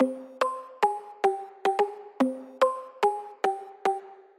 描述：J芥末风格的旋律拨动
Tag: 109 bpm Hip Hop Loops Bells Loops 759.73 KB wav Key : Unknown Logic Pro